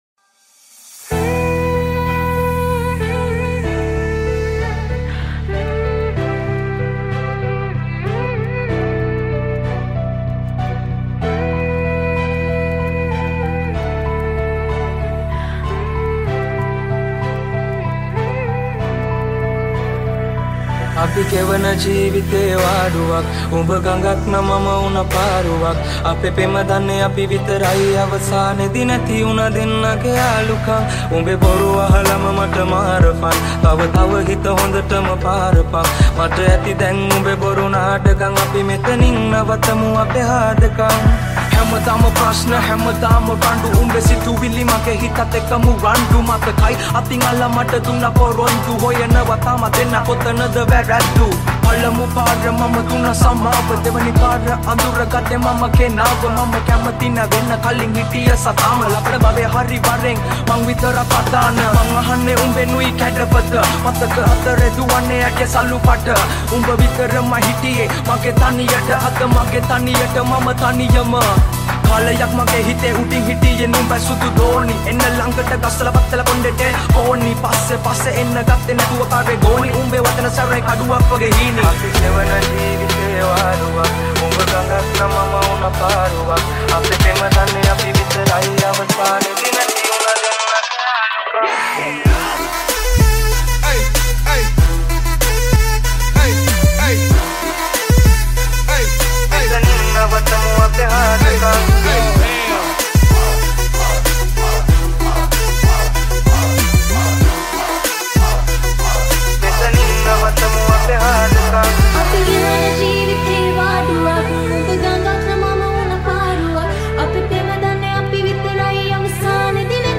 High quality Sri Lankan remix MP3 (4.1).